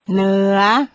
เหนือ  neuuaR